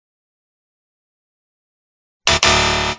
Game Over
Game Over is a free ui/ux sound effect available for download in MP3 format.
368_game_over.mp3